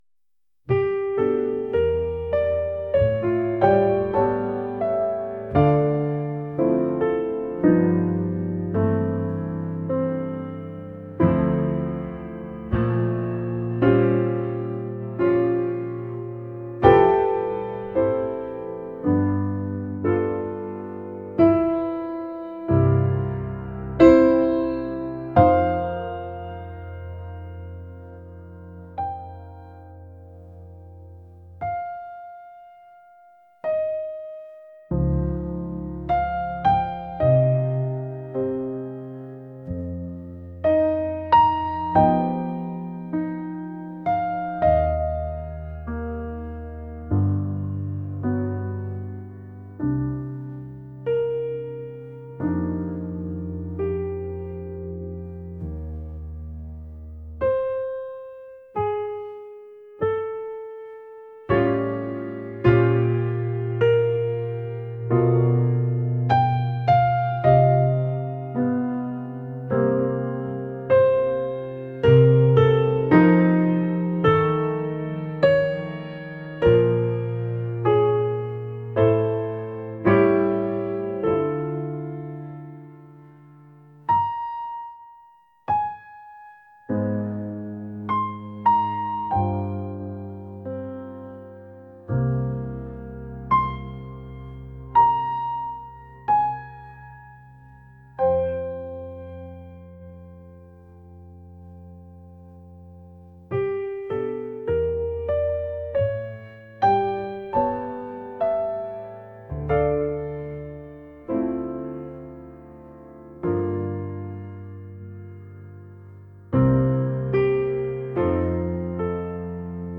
romantic | smooth | jazz